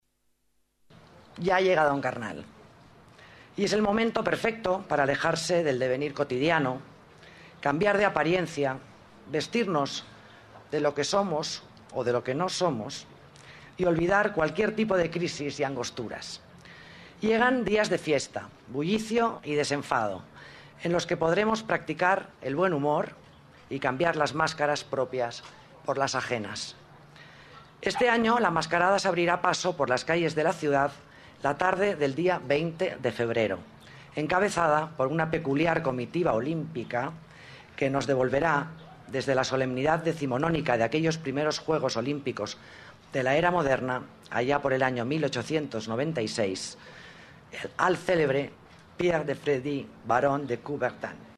Nueva ventana:Declaraciones de la delegada de Las Artes, Alicia Moreno: Carnaval 2009